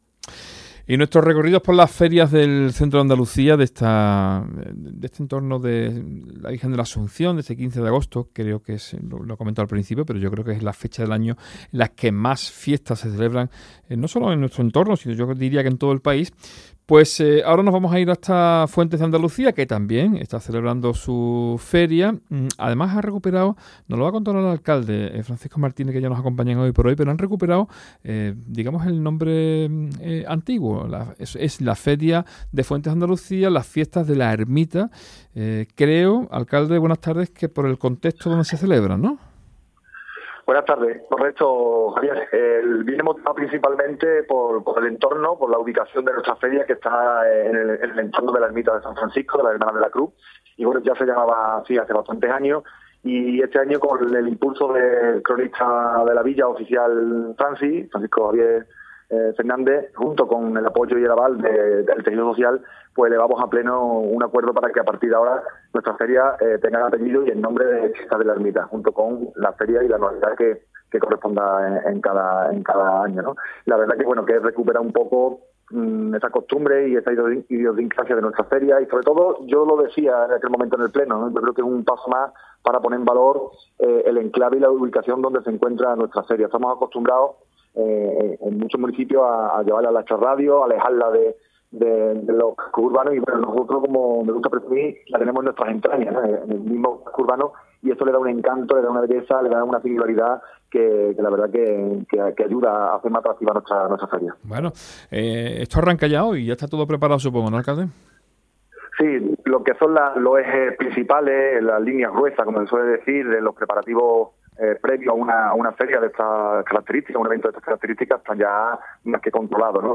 ENTREVISTA | Francisco Martínez (alcalde de Fuentes de Andalucía)